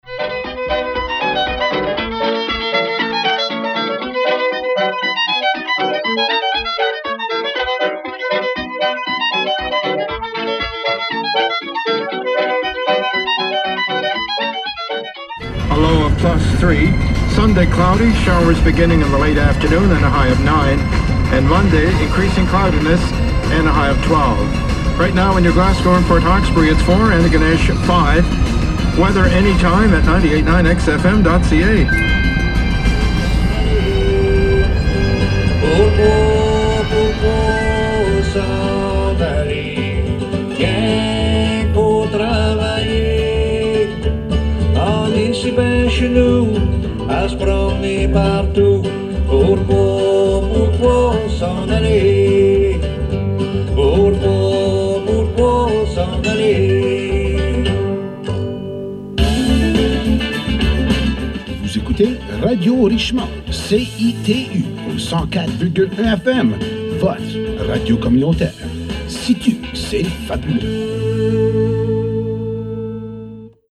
Point météo en anglais